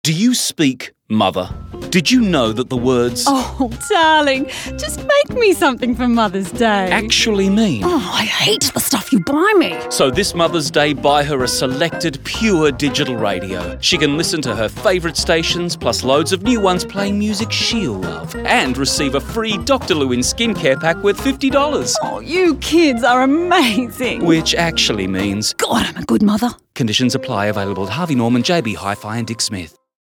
The four 30 second radio ads promote digital radios as the perfect gift and the retailer’s special offer of a free Dr Lewinn skincare pack when one of three selected Pure digital radios are purchased.